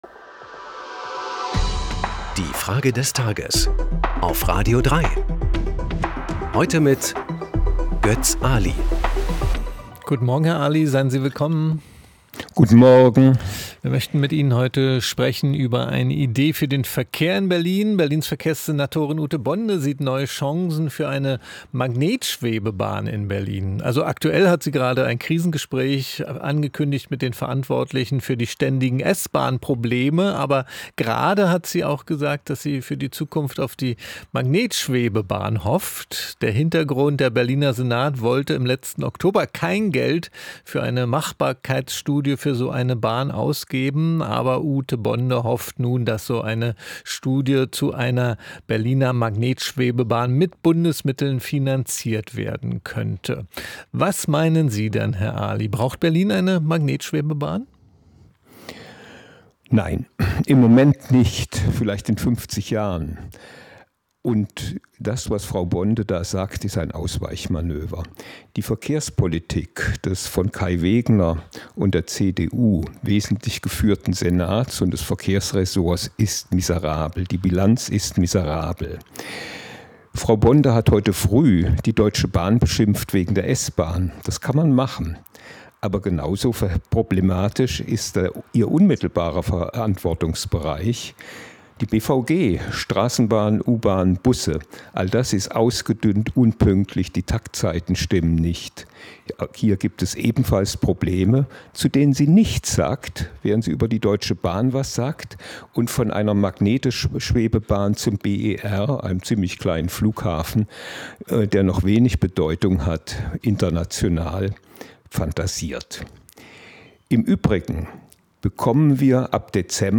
Wir fragen unseren Kommentator Götz Aly: